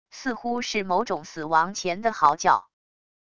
似乎是某种死亡前的嚎叫wav音频